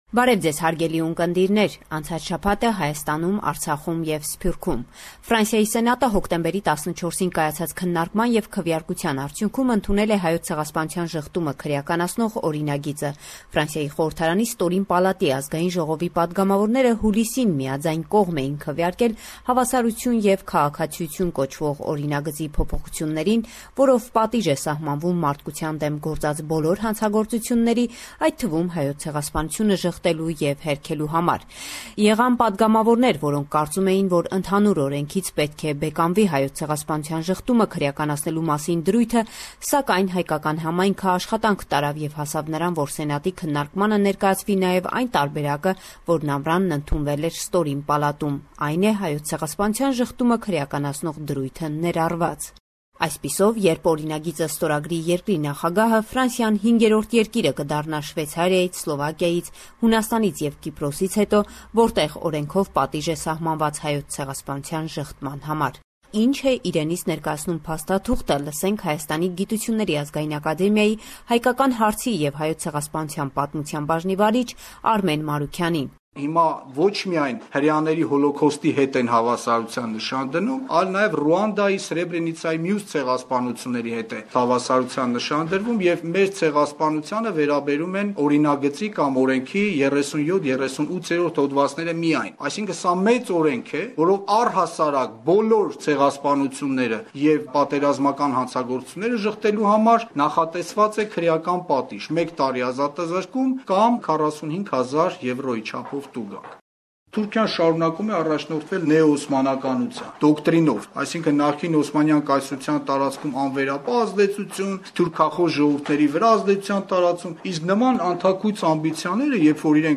Latest News – 18 October 2016